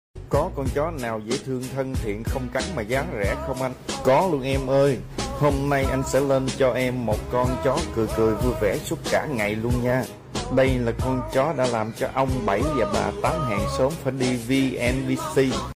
có cười cười vui vẻ sound effects free download